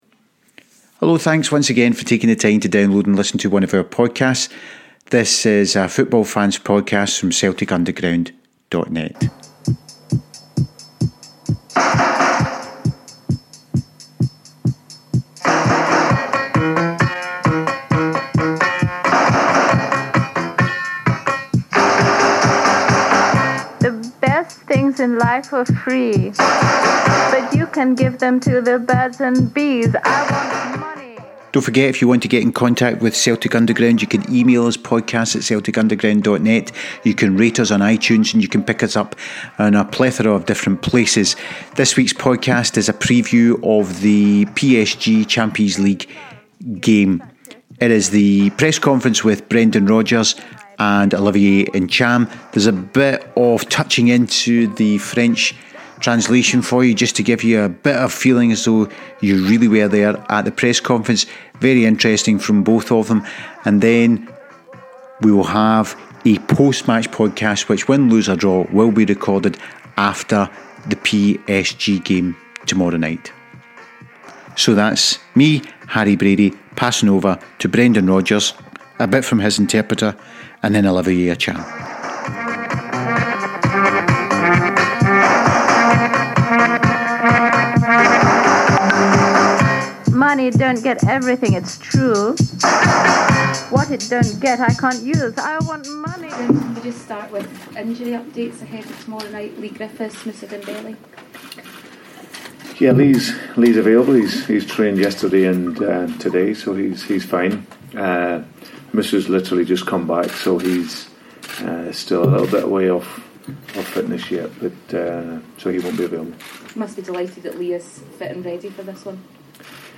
Prior to the UCL first group stage game, there was the usual round of press conferences and we managed to get the audio from Brendan and Olivier Ntcham pre-game. There is a wee bit of the translators in there and also if it sounds like Olivier is answering some questions with a French accent & some with a Scots one, it’s because when asked a question in French he replied in French and therefore we have the translator answer only.